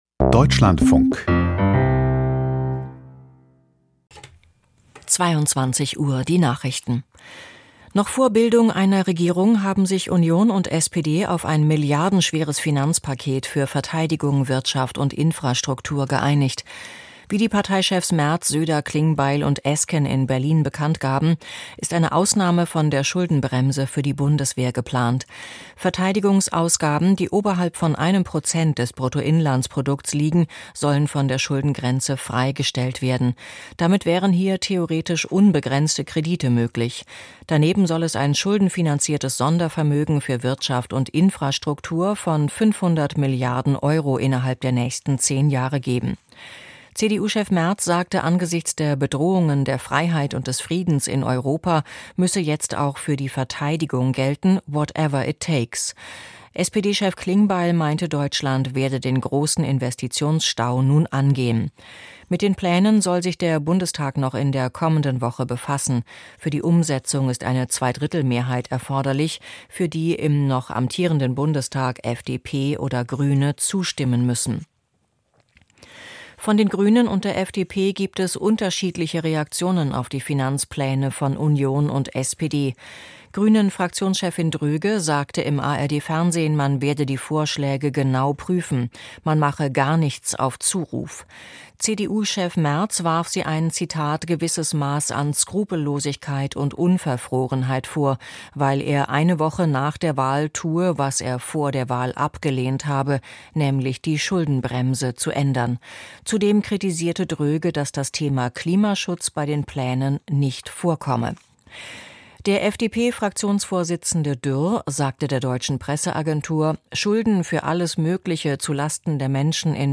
Ukrainische Angriffe und Diplomatie: Interview